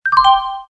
chimes.mp3